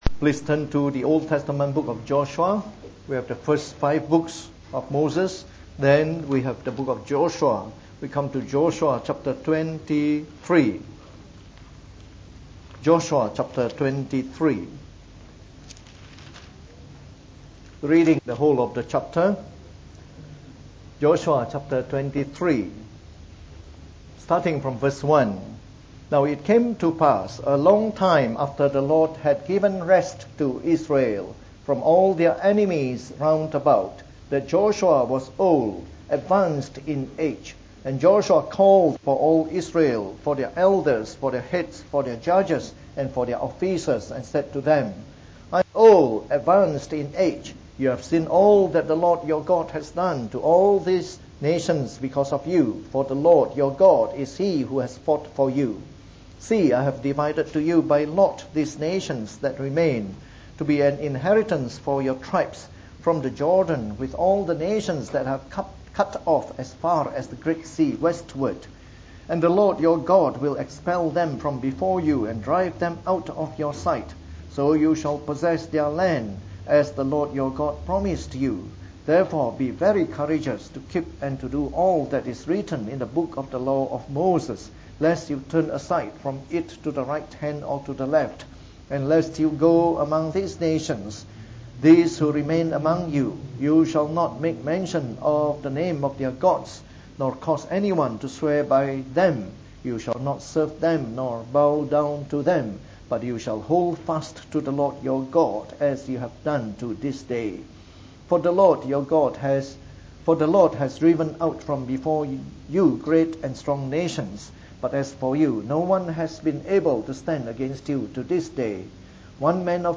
Preached on the 4th of January 2015. From our series on the Book of Joshua delivered in the Morning Service.